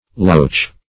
Loach \Loach\ (l[=o]ch), n. [OE. loche, F. loche.] (Zool.)